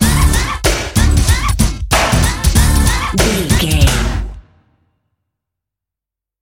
Aeolian/Minor
drum machine
synthesiser
Eurodance